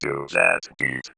VVE1 Vocoder Phrases 12.wav